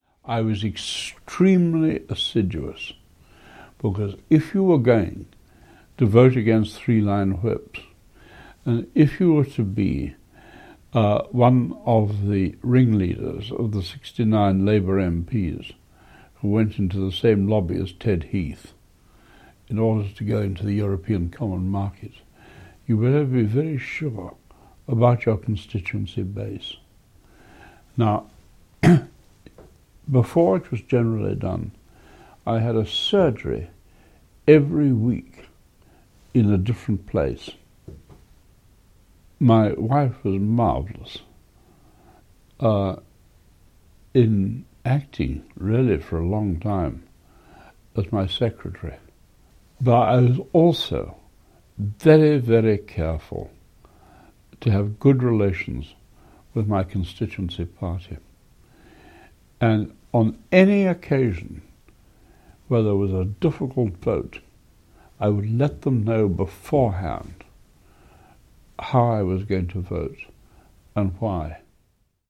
Even in the setting of an oral history interview he proved formidable: seizing our interviewer’s notes before starting, he often seemed to be conducting the interview himself!